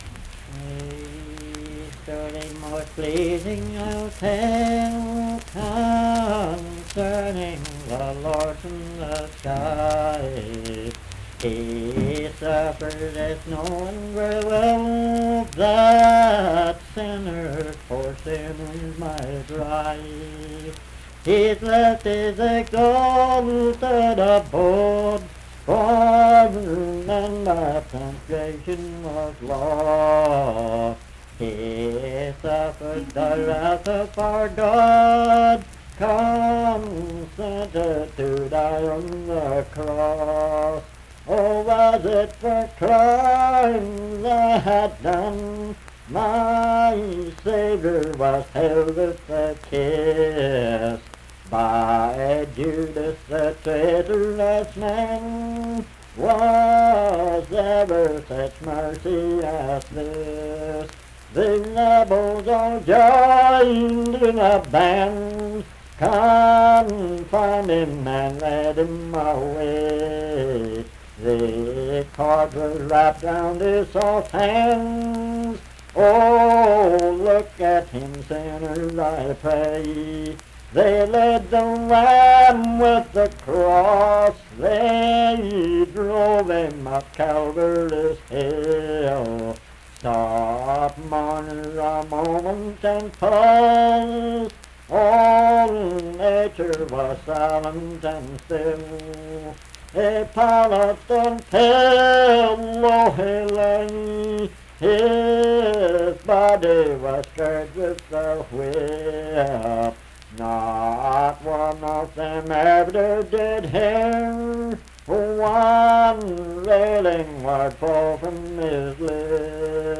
Unaccompanied vocal music
Verse-refrain 11(4). Performed in Dundon, Clay County, WV.
Hymns and Spiritual Music
Voice (sung)